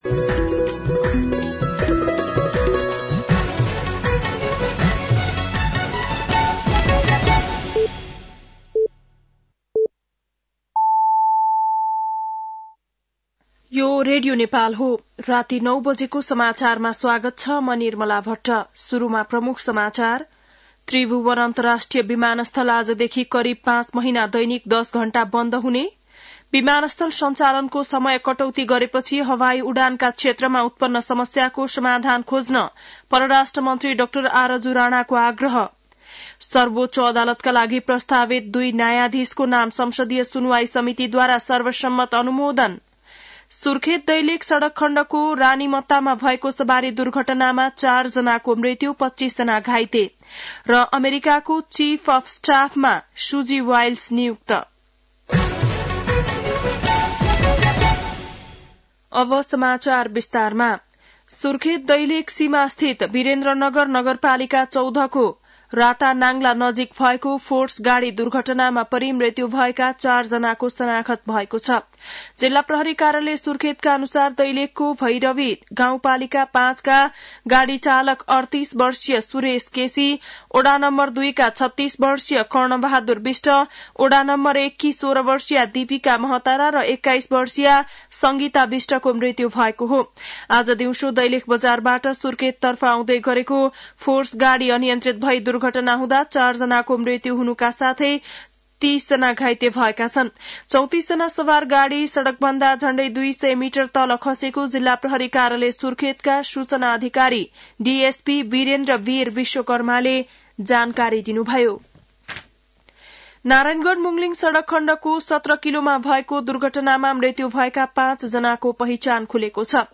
बेलुकी ९ बजेको नेपाली समाचार : २४ कार्तिक , २०८१